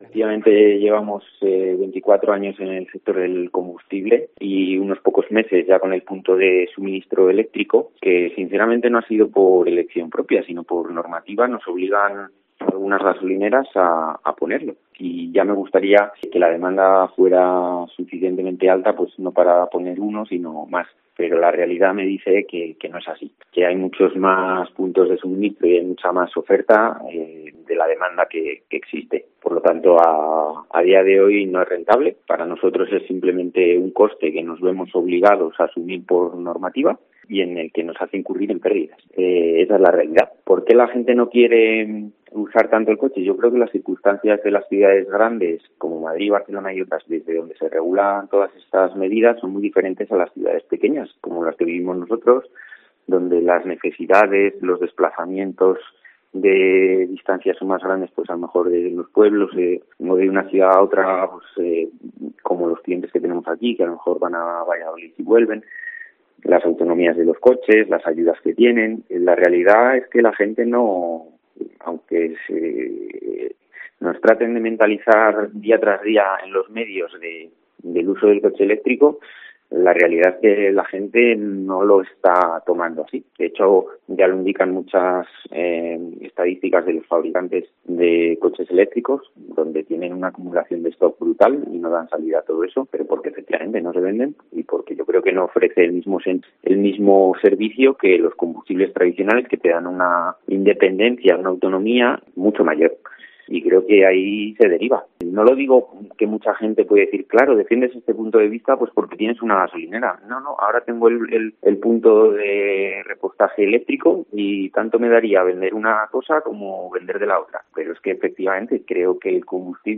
COPE Salamanca entrevista